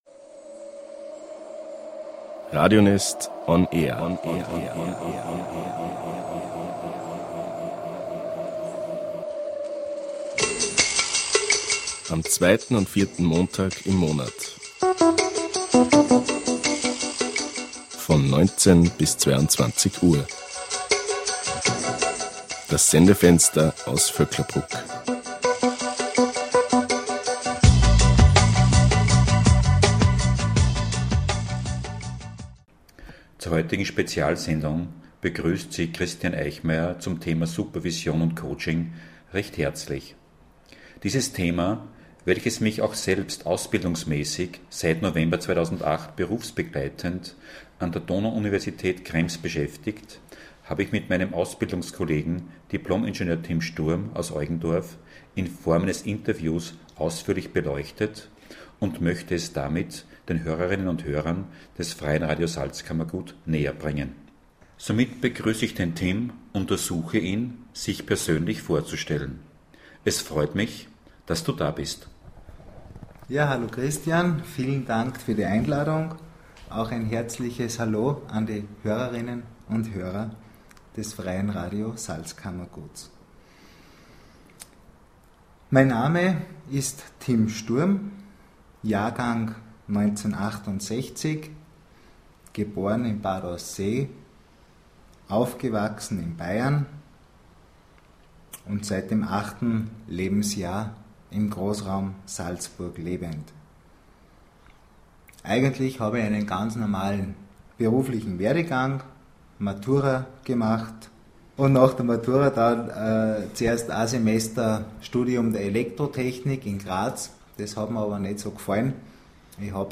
Das Interview besteht aus 20 Fragen und meinen Antworten dazu.
Interview-Radio-Sendung-Supervision-Coaching.mp3